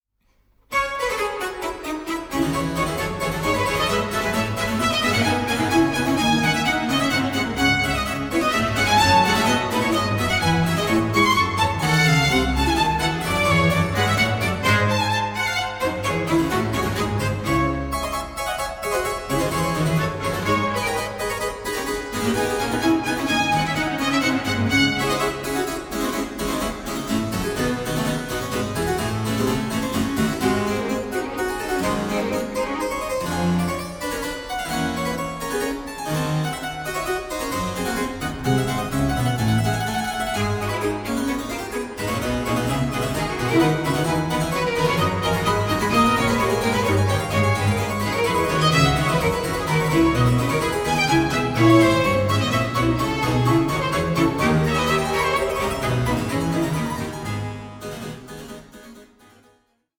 (48/24, 88/24, 96/24) Stereo  14,99 Select